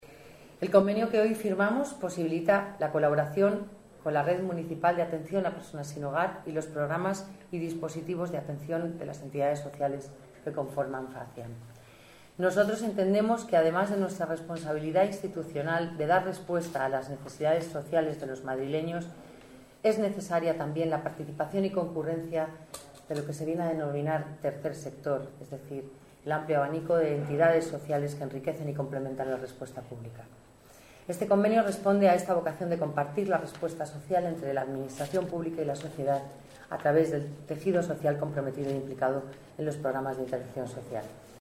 Nueva ventana:Palabras de la delegada de Familia y Servicios Sociales, Dolores Navarro.